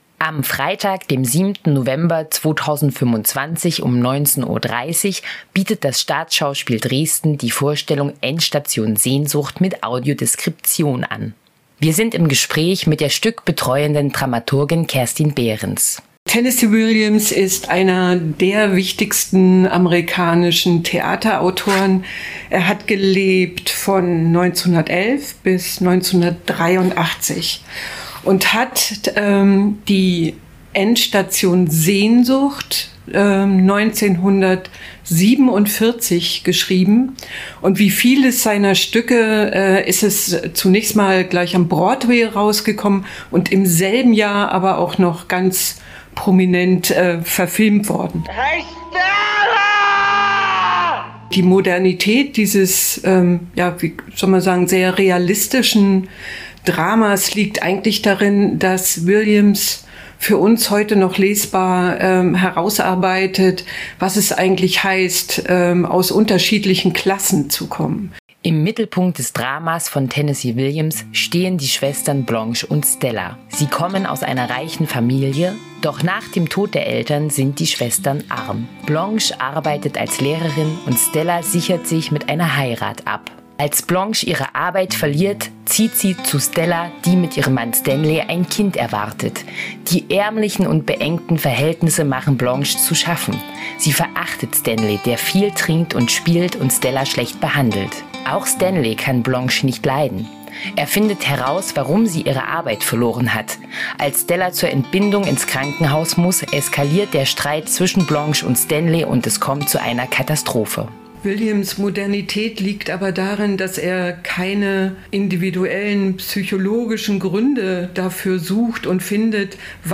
Im Anhang finden Sie unseren Hörflyer als mp3-Datei zur Inszenierung sowie eine barrierefreie PDF-Datei.